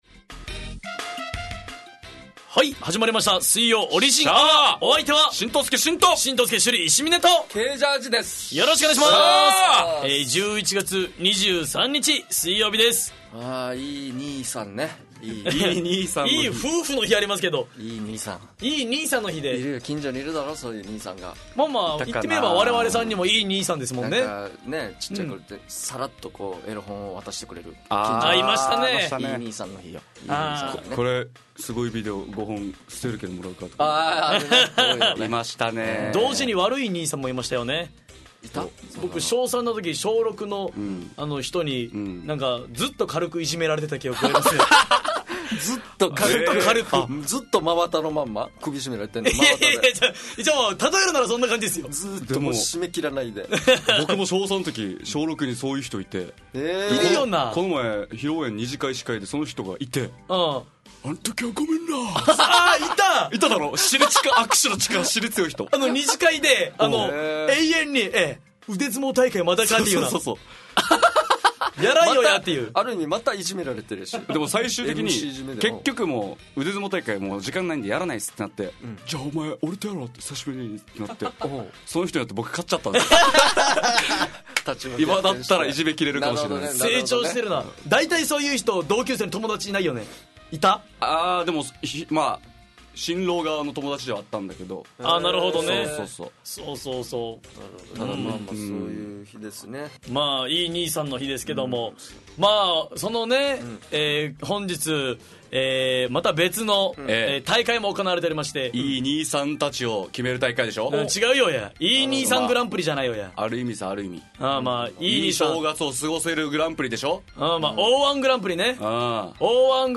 fm那覇がお届けする沖縄のお笑い集団オリジンと劇団O.Z.Eメンバー出演のバラエティ番組!